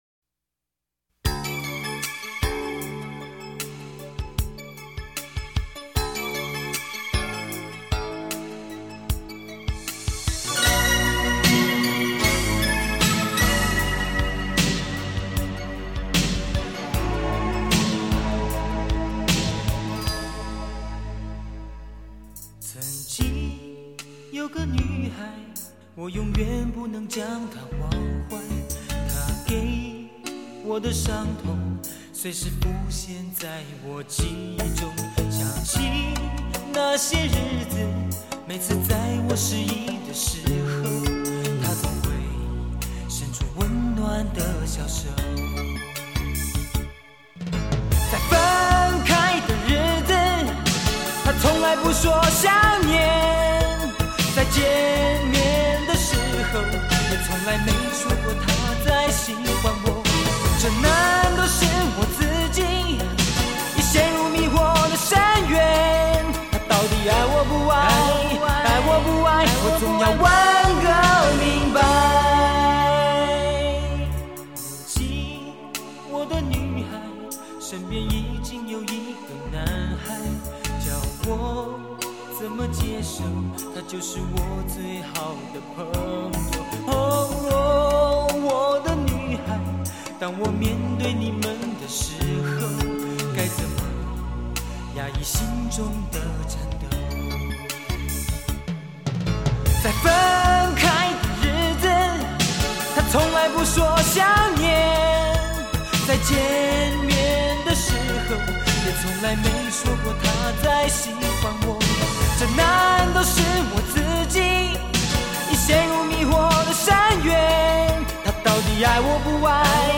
青春新派伤感情歌